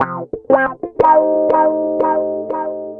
Track 13 - Clean Guitar Wah 04.wav